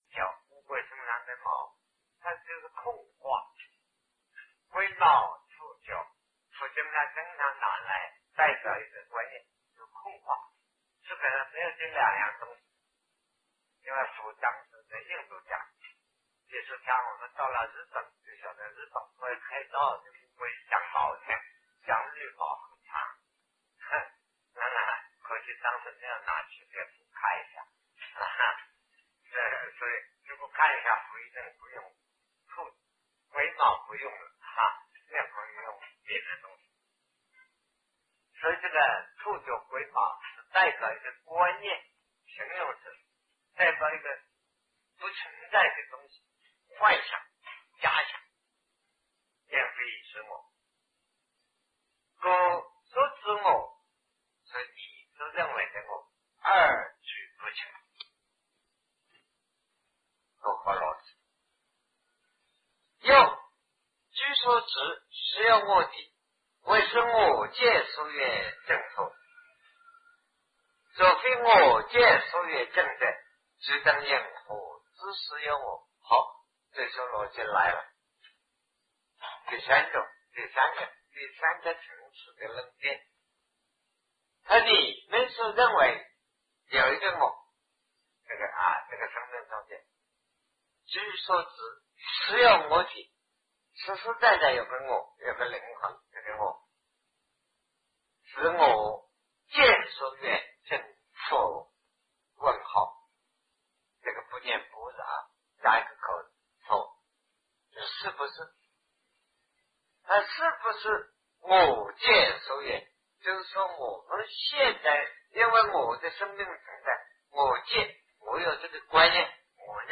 我与无我的论辩 南师讲唯识与中观（1980代初于台湾052(下)